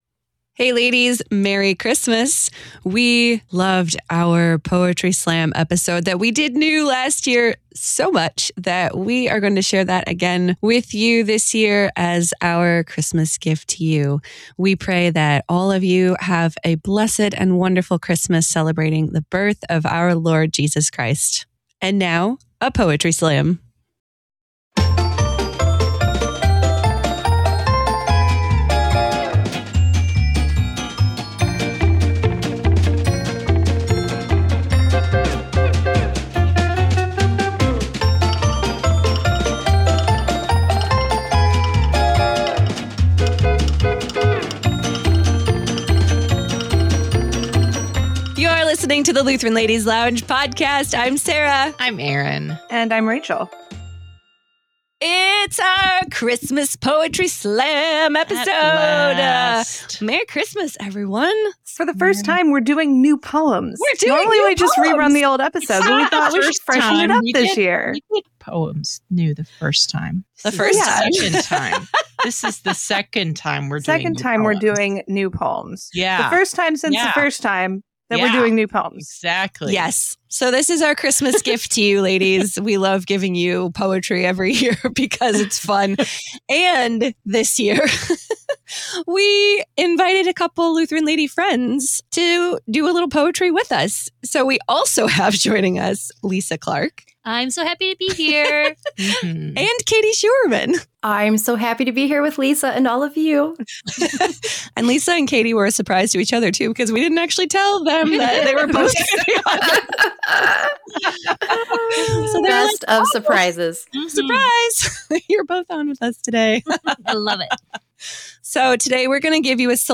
Together, they share original Christmas poems ranging from psalms of lament to epic odes to hilarious parodies.